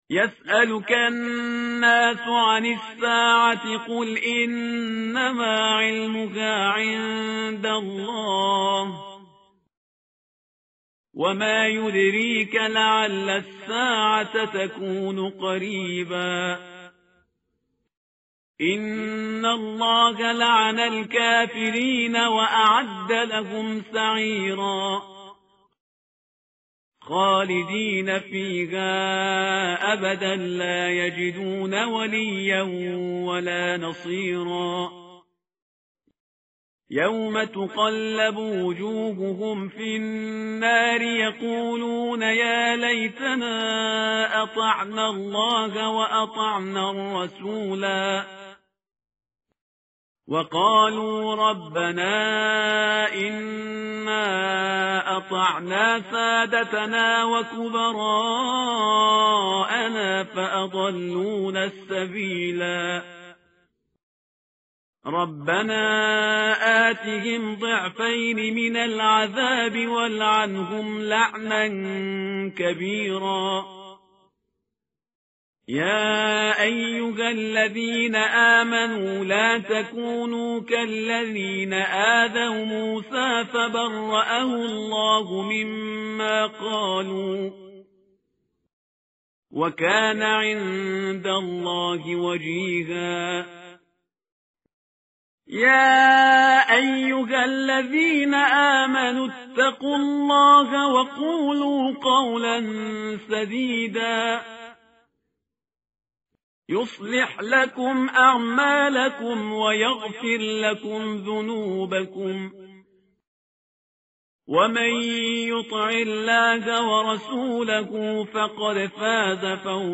متن قرآن همراه باتلاوت قرآن و ترجمه
tartil_parhizgar_page_427.mp3